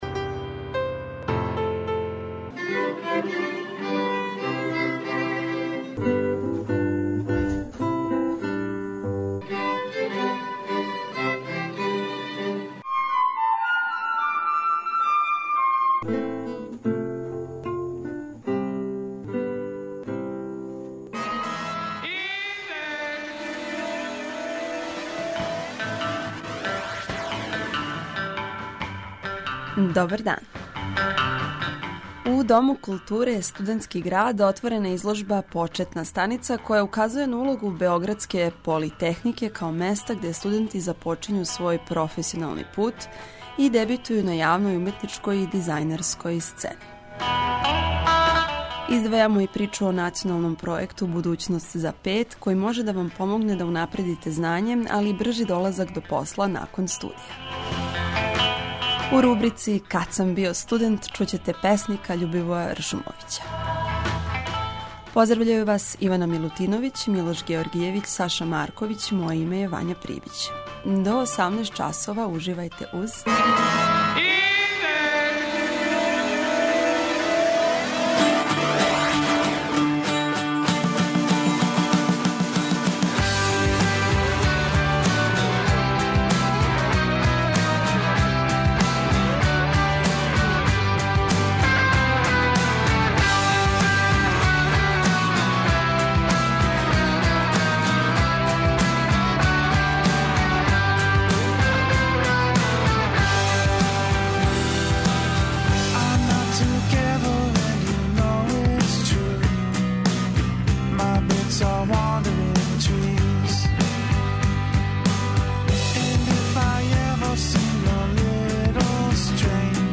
У рубрици "Кад сам био студент", чућете песника Љубивоја Ршумовића .
преузми : 27.22 MB Индекс Autor: Београд 202 ''Индекс'' је динамична студентска емисија коју реализују најмлађи новинари Двестадвојке.